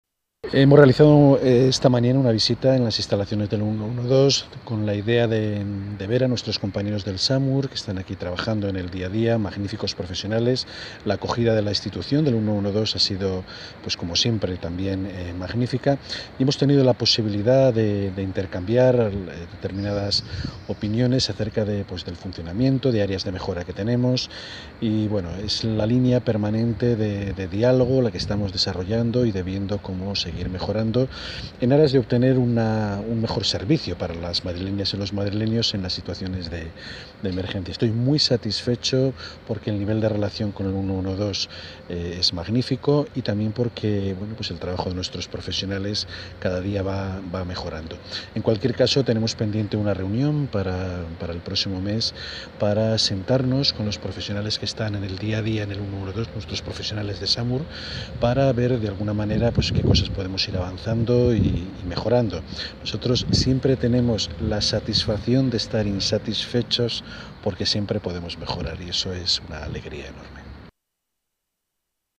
Nueva ventana:Sonido de Barbero, en la visita institucional a Madrid 112